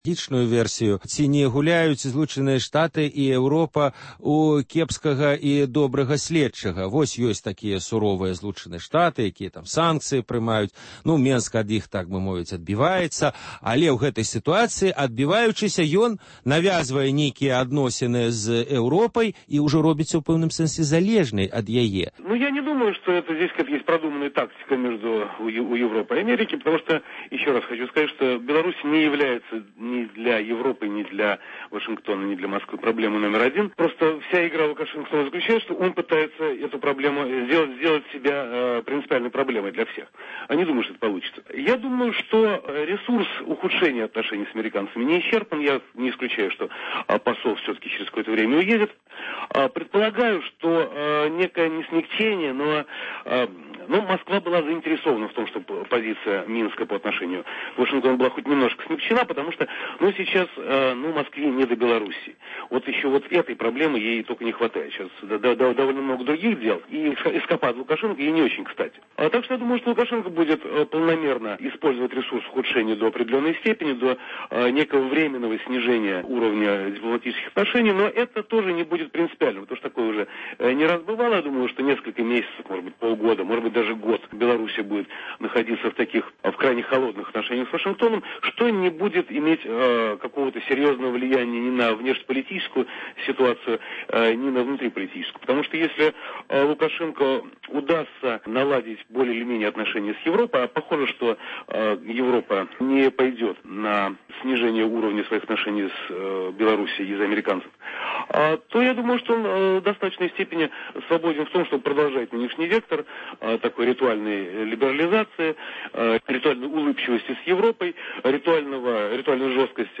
Агляд тэлефанаваньняў за тыдзень.